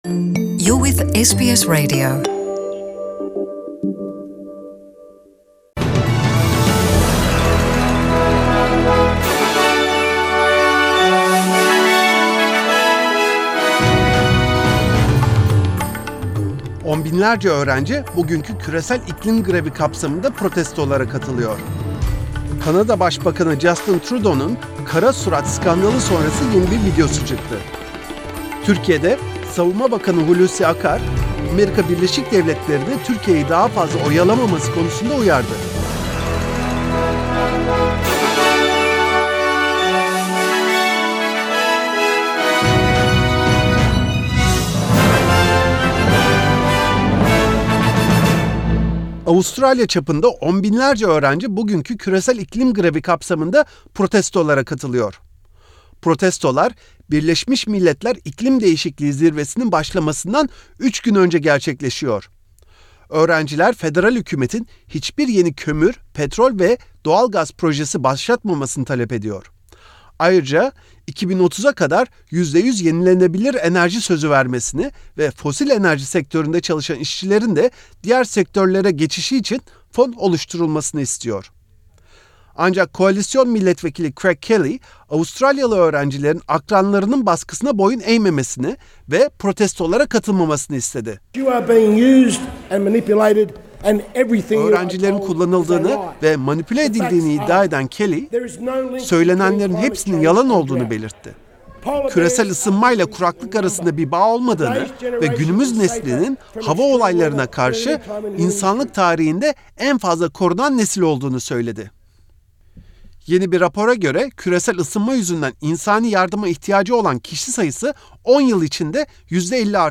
SBS Turkish News